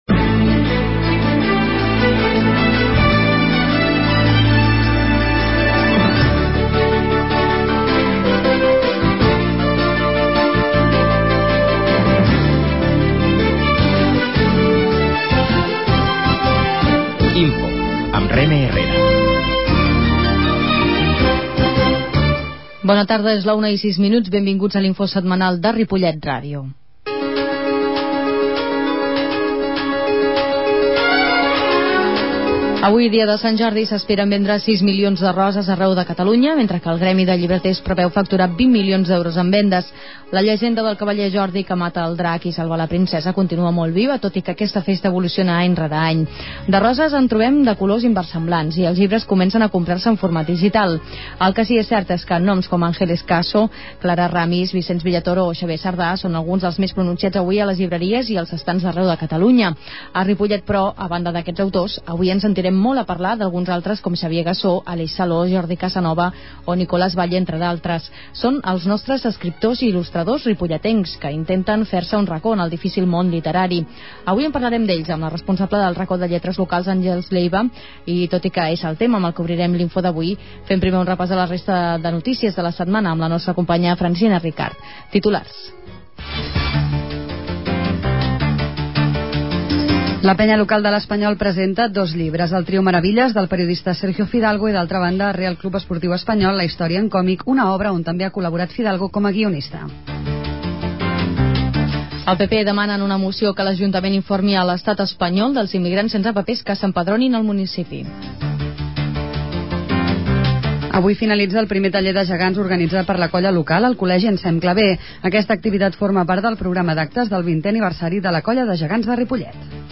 La qualitat de so ha estat redu�da per tal d'agilitzar la seva desc�rrega.